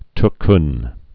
(tchn, djün)